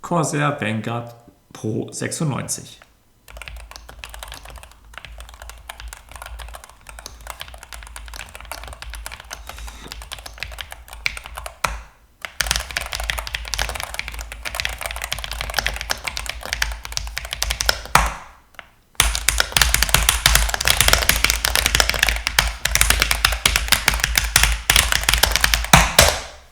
Akustik: Angemessen
Das führt zu einer Akustik, die Anschläge klar betont und sich mit „vier“ Schichten Geräuschdämpfung klar von der günstigeren, äußerlich ähnlichen K70 Pro TKL (Test) abhebt.
An eine Makr 75 mit Metallgehäuse und umschlossenen Tasten kommt die Vanguard jedoch nicht heran, am Anschlag fehlt dem Feedback eine Spur Klarheit. Ganz so satt und tief ist der Klang zudem nicht.
Die Vanguard ist ruhig, kein Leisetreter.